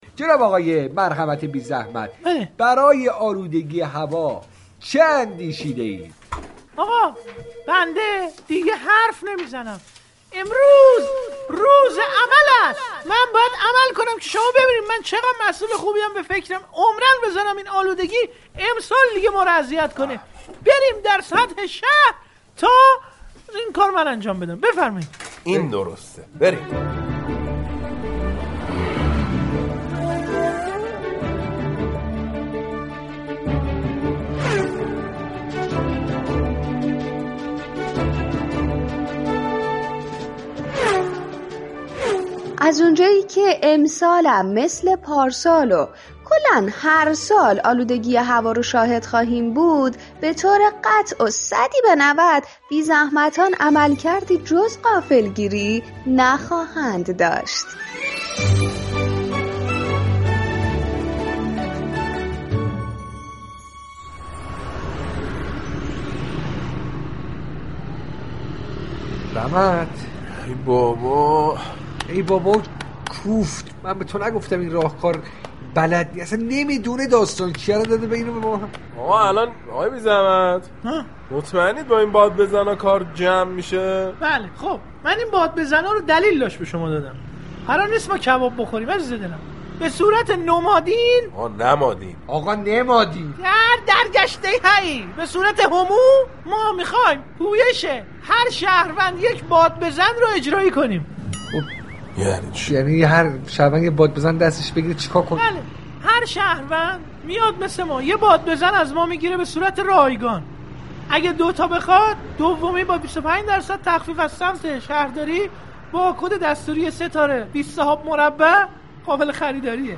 ین برنامه در قالب نمایش رادیویی با حضور شخصیت های «خبرنگار، مسئول و راننده» هر روز یك نمایش به بازتاب مشكلات جامعه در بخش های مختلف فرهنگی، اجتماعی و اقتصادی می پردازند.